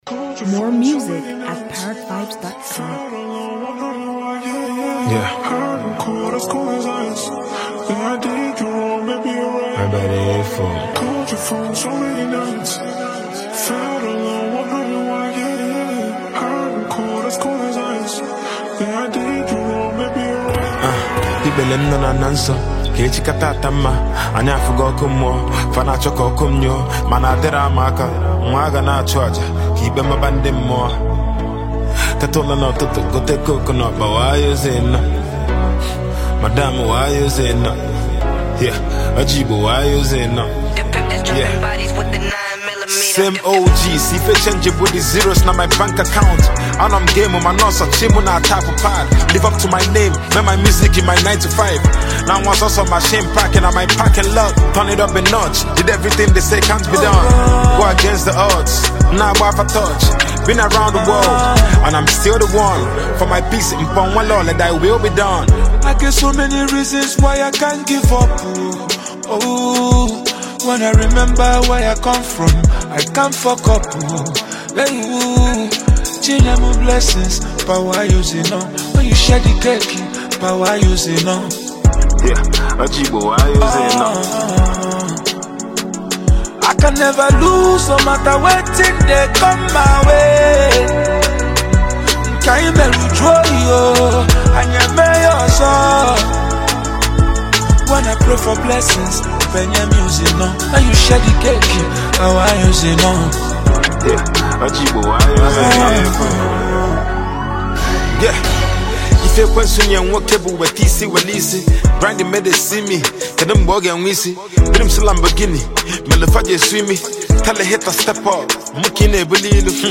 Nigerian indigenous rap genius
heavyweight Nigerian indigenous singer, highlife sensation